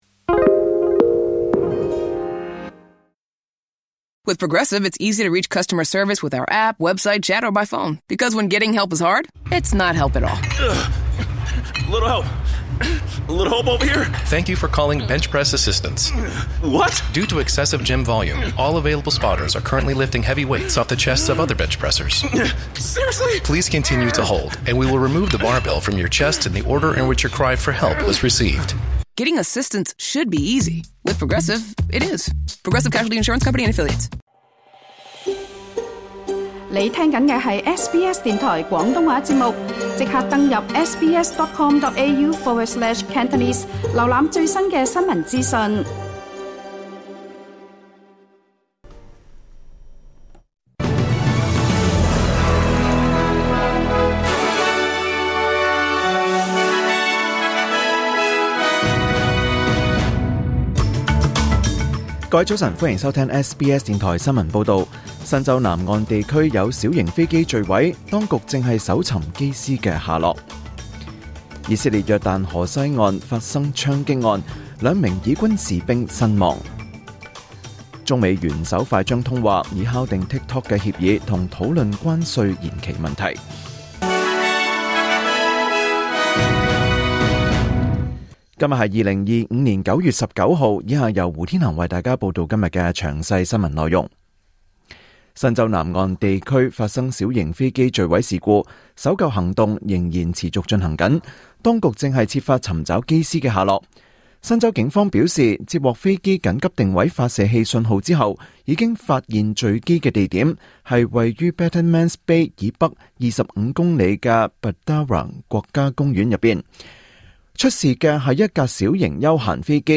2025年9月19日 SBS 廣東話節目九點半新聞報道。